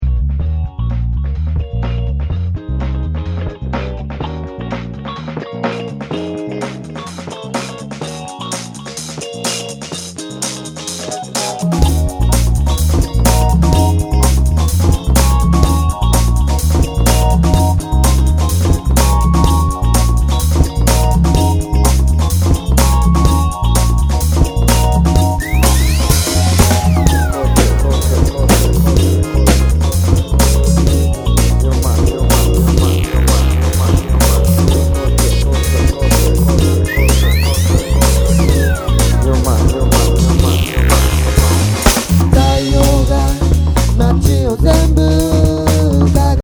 前作とはかなり様相を変え、ハウスやラウンジを強く意識した内容になっている。
全曲にポップな歌がフューチャーされている。